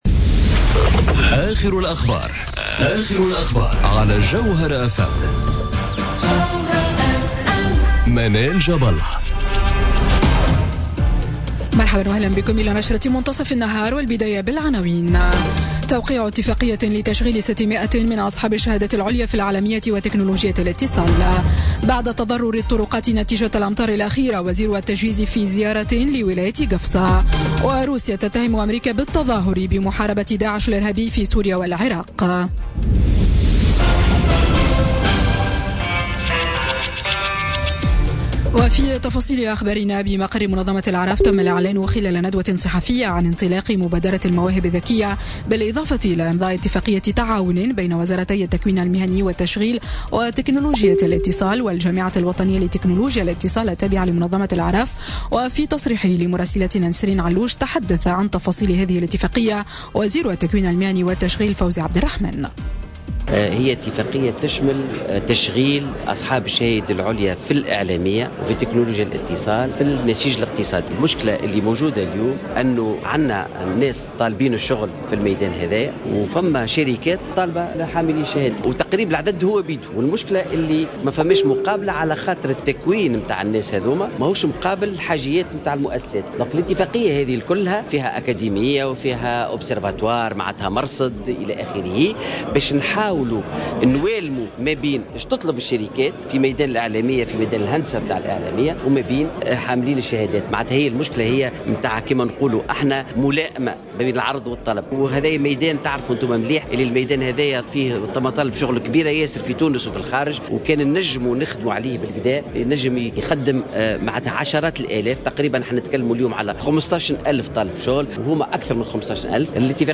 نشرة أخبار منتصف النهار ليوم الثلاثاء 10 أكتوبر 2017